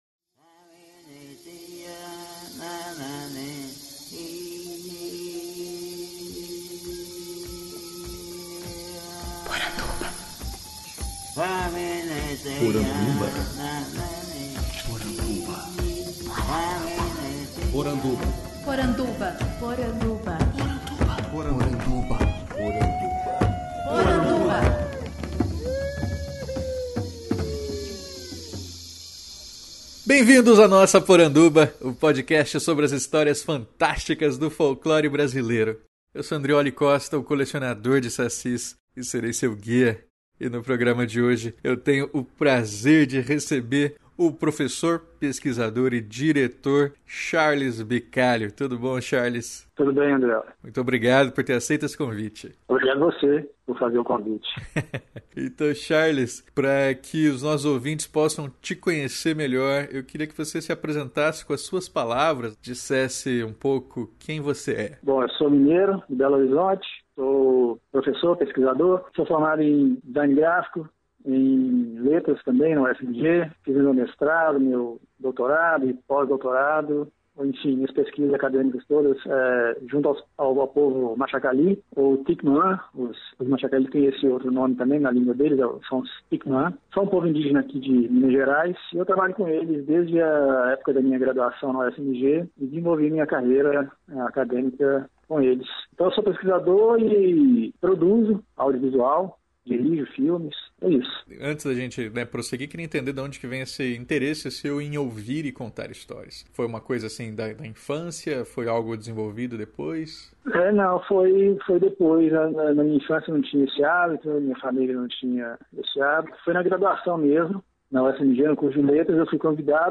Podcast que entrevista o pesquisador, diretor e roteirista